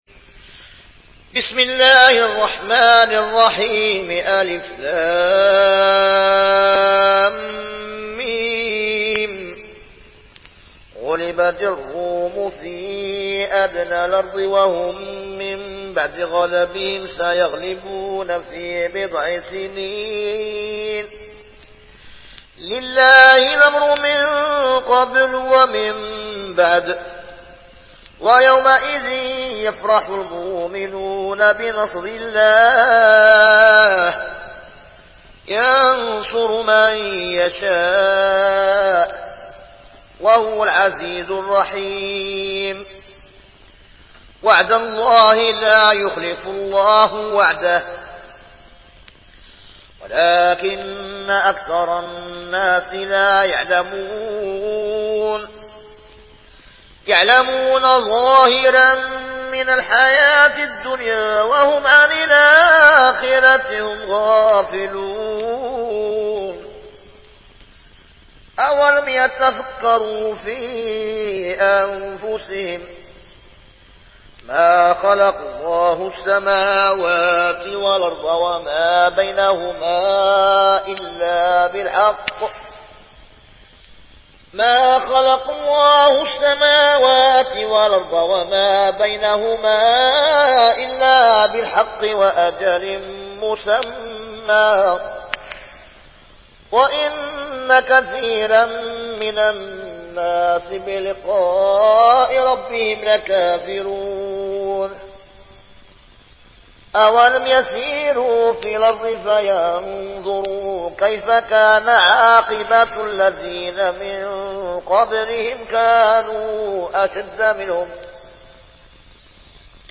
Riwayat Warsh dari Nafi